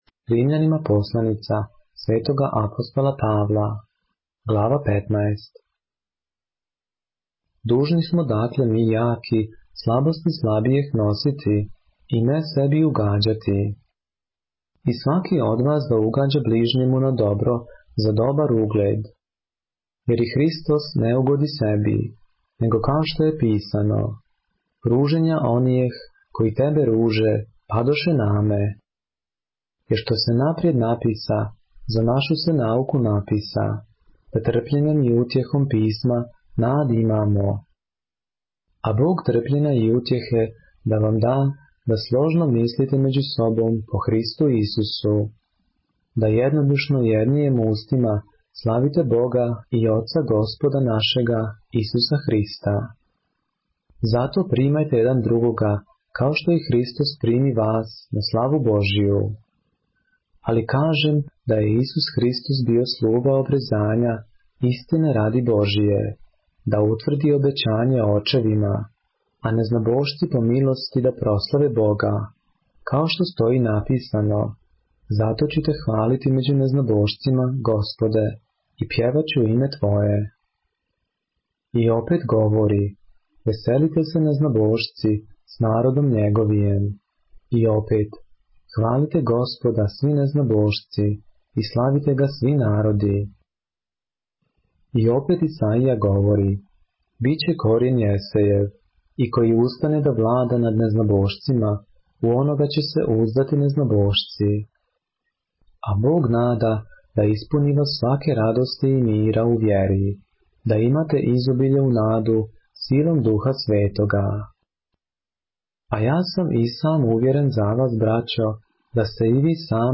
поглавље српске Библије - са аудио нарације - Romans, chapter 15 of the Holy Bible in the Serbian language